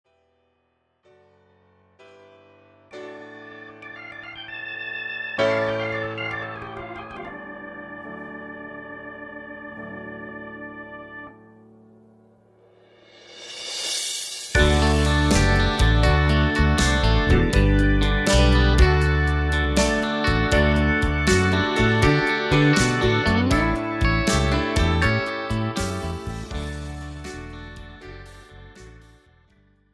This is an instrumental backing track cover.
• Key – D
• With Backing Vocals
• No Fade